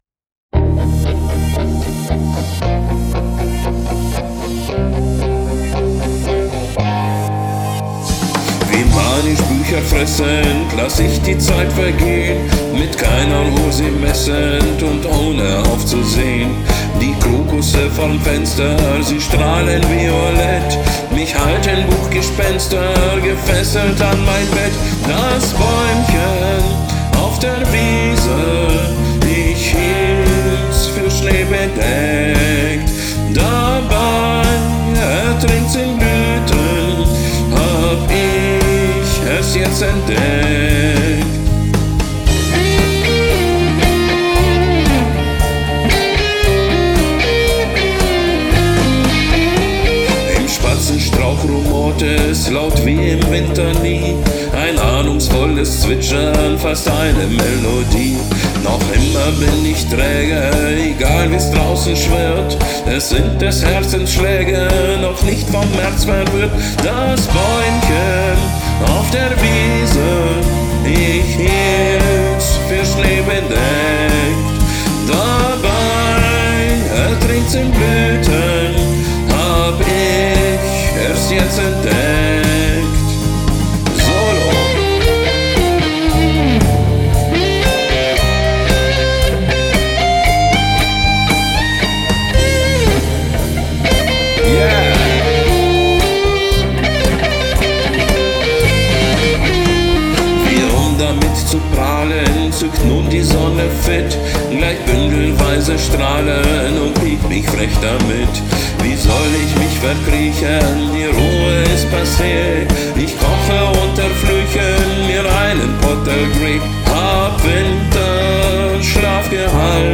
Also einfach mal die Arrangement-Servier-Vorschläge versucht zu kopieren, bei Tempo 122. Nur dass ich das Schema iv-V-I-V nutzte.